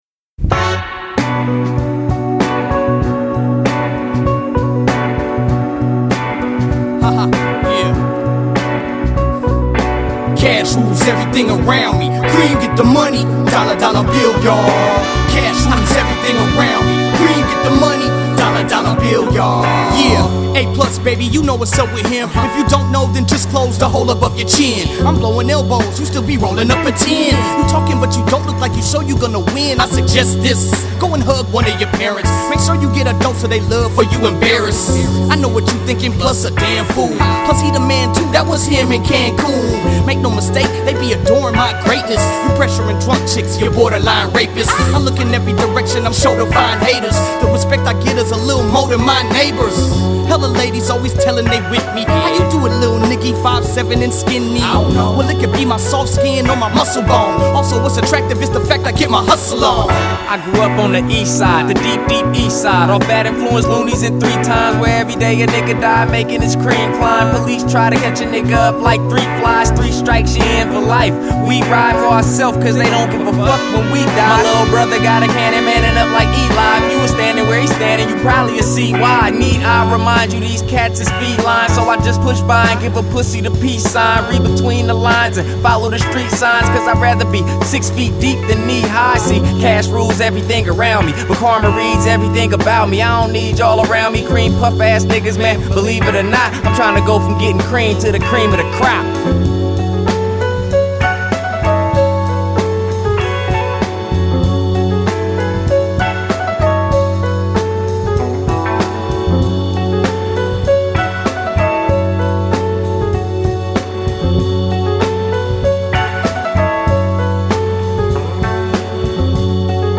funk and soul remixes